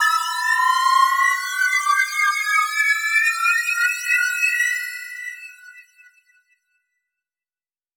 MB Trans FX (4).wav